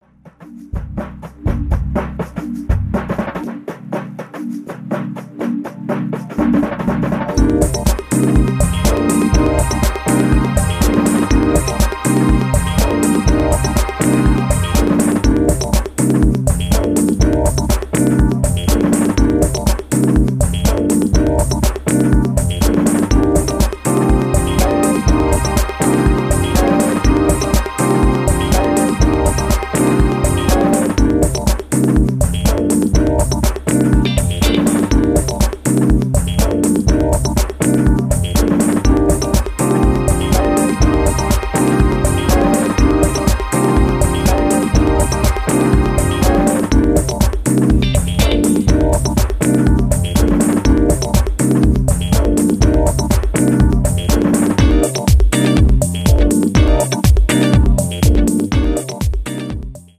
ディープで黒々とした雰囲気が充満した濃厚ビートダウン・ハウス！